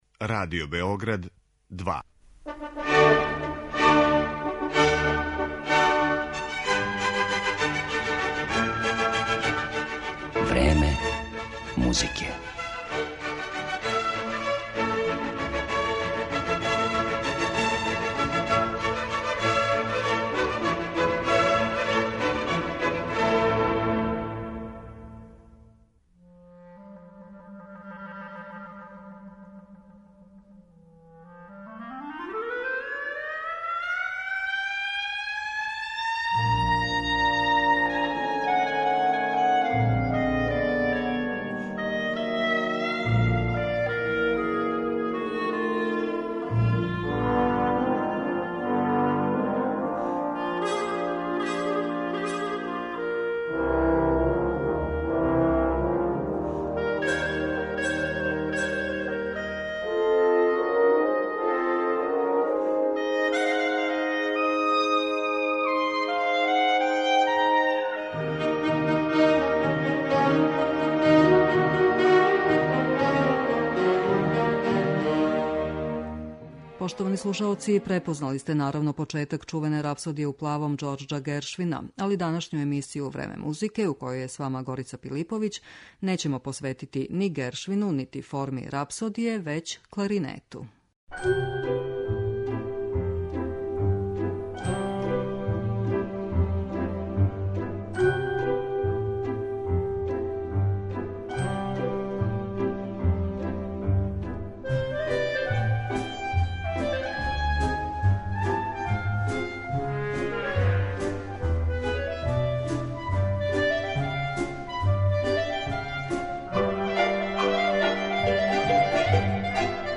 МУЗИКА ЗА КЛАРИНЕТ
У емисији можете слушати фрагменте концерата, соната и других композиција где је кларинет у центру пажње, или бар својом појавом даје специфичну боју музици.